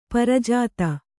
♪ parajāta